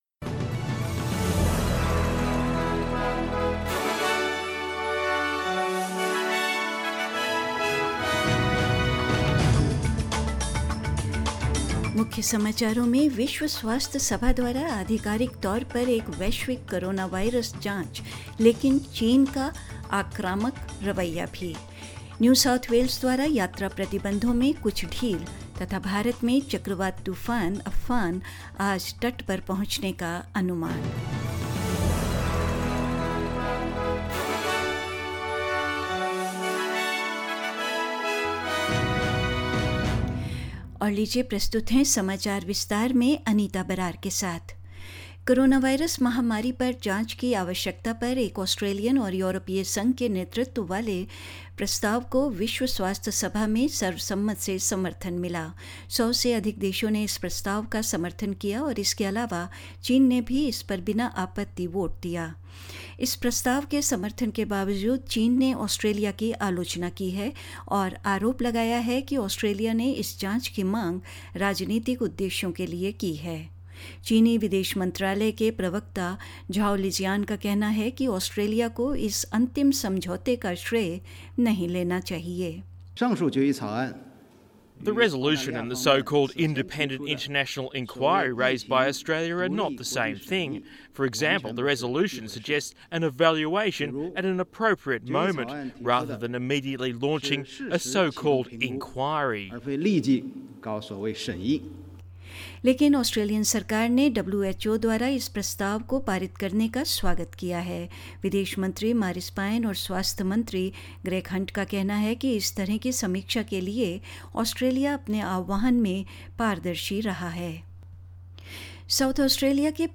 HIndi News 20th May 2020